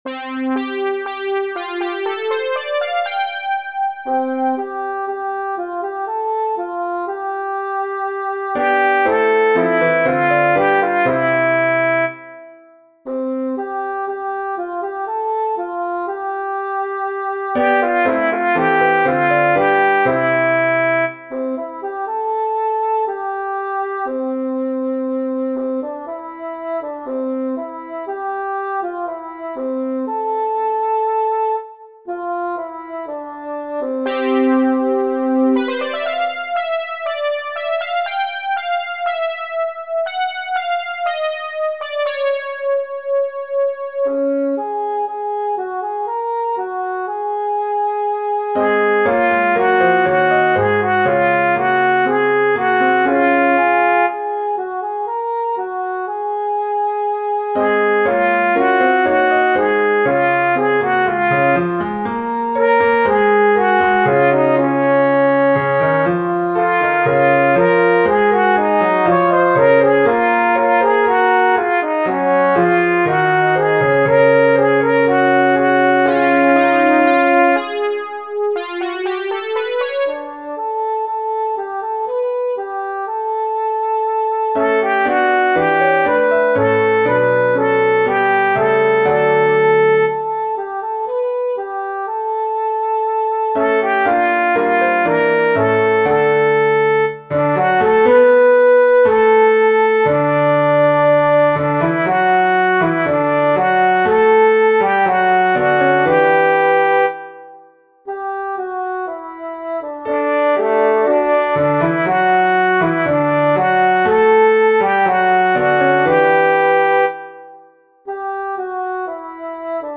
In these recordings, the soloist sounds like a bassoon. The featured voice sounds like a trombone.
pax_christi-alto-1.mp3